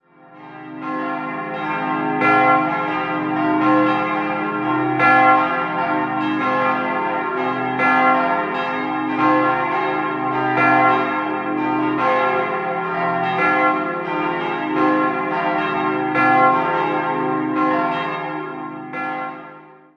Idealquartett: c'-es'-f'-as'
Aus statischen Gründen musste im Turm eine Gegenpendelanlage eingebaut und die Glocken großteils mit Reversionsklöppeln ausgestattet werden.
bell